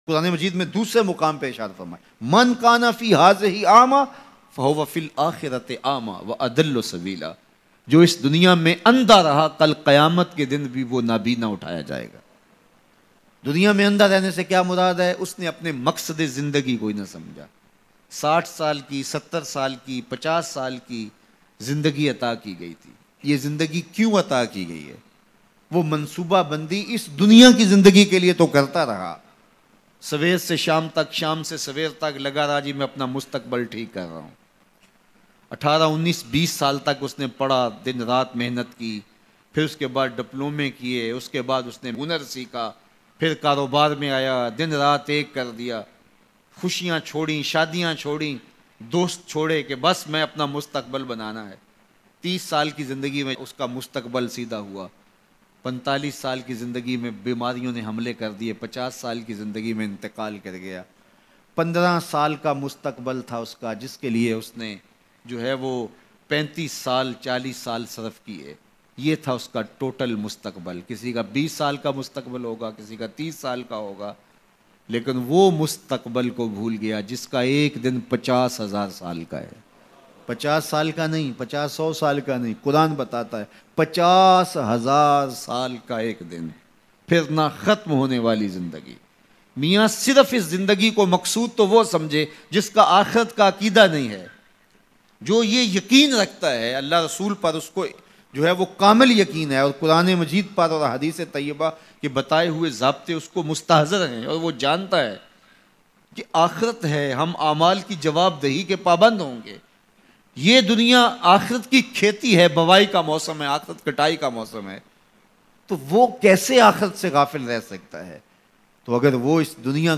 Bayan MP3